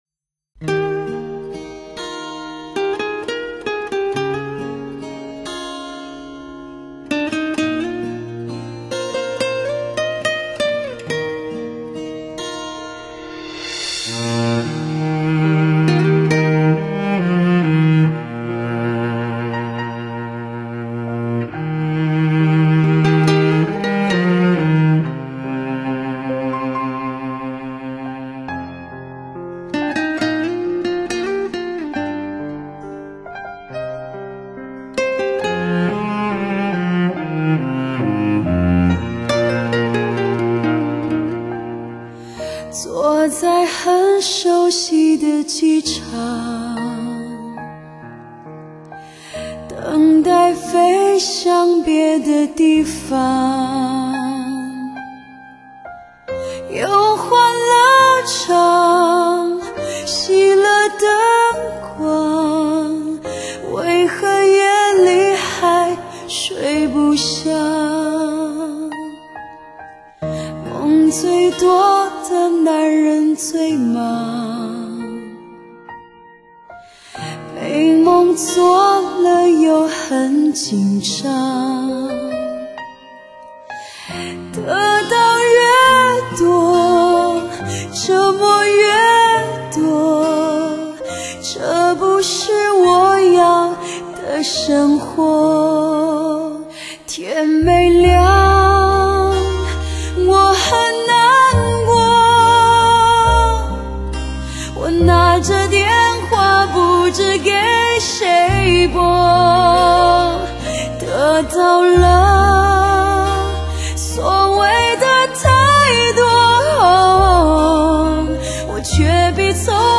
这需要有怎样的经历才能如此成熟，才能让喉咙暗哑但激情仍在。
（试听曲为低品质wma，下载为320k/mp3）